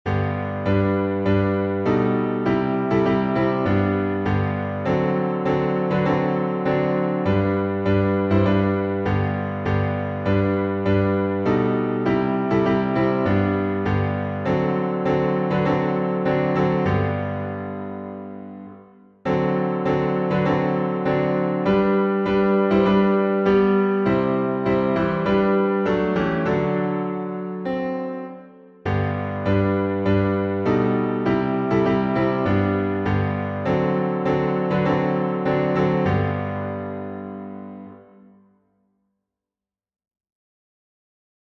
Tell It to Jesus — G major.
Key signature: G major (1 sharp) Time signature: 4/4